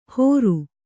♪ hōru